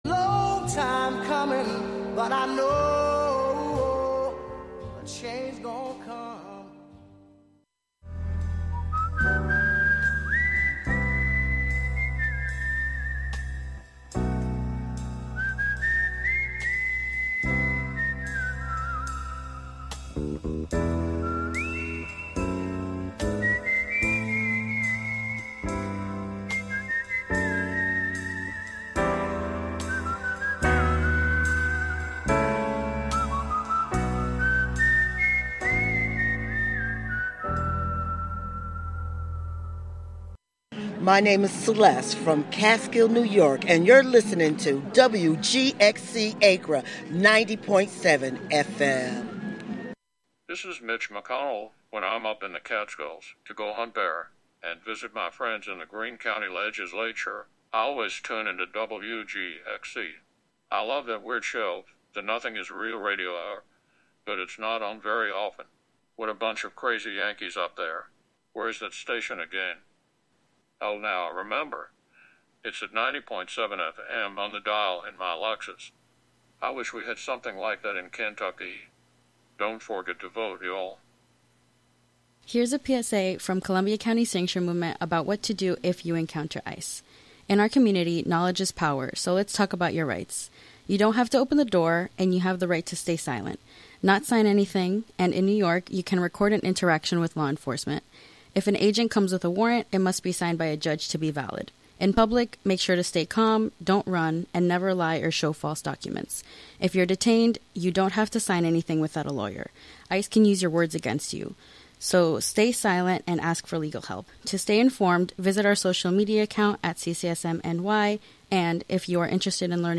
On this monthly program, “La Ville Inhumaine” (The Inhuman City), you will hear music, found sounds, words, intentional noise, and field recordings all together, all at once.